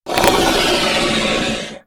revenant_dies.ogg